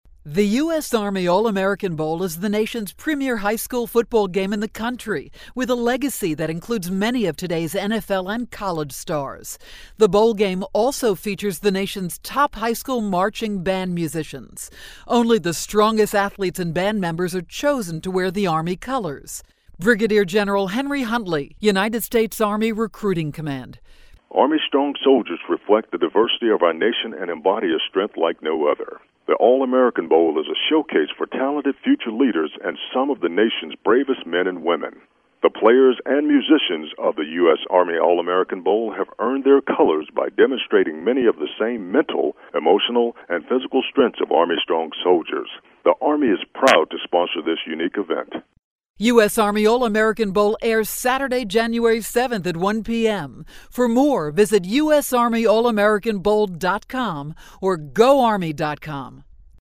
January 3, 2012Posted in: Audio News Release